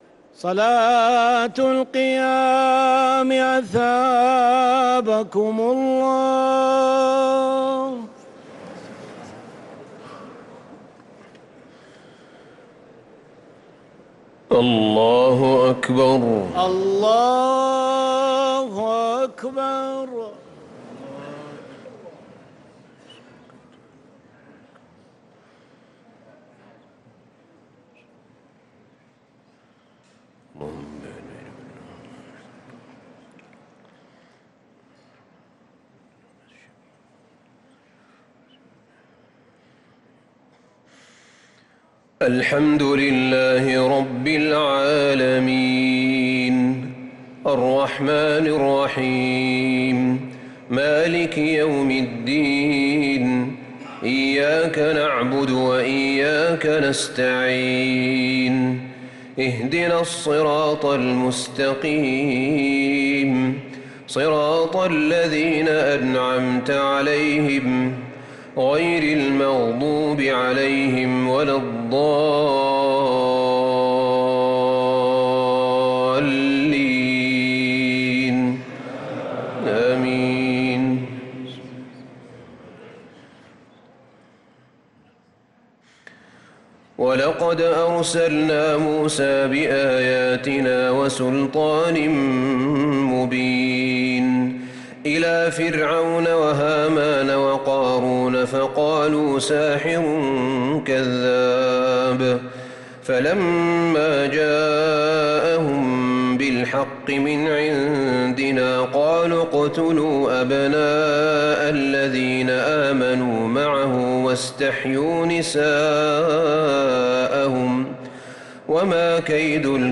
صلاة التراويح ليلة 26 رمضان 1445 للقارئ أحمد بن طالب حميد - الثلاث التسليمات الأولى صلاة التراويح